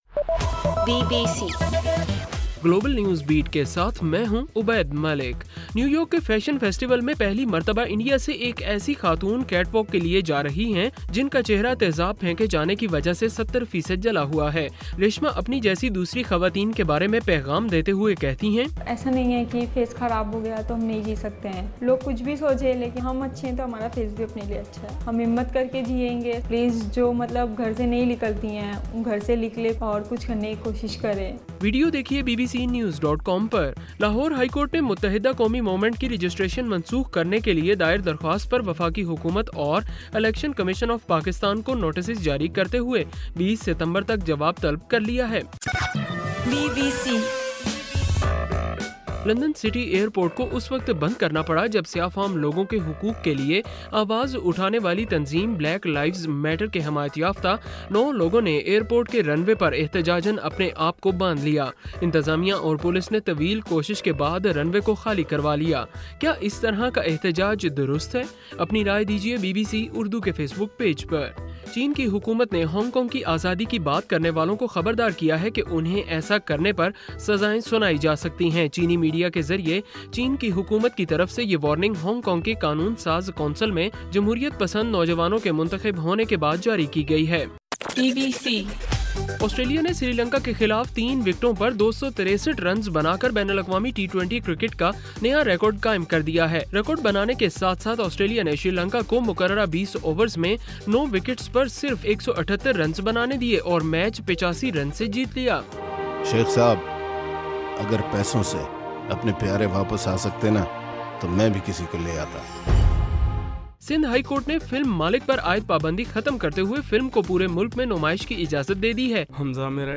ستمبر 07 : صبح 1 بجے کا گلوبل نیوز بیٹ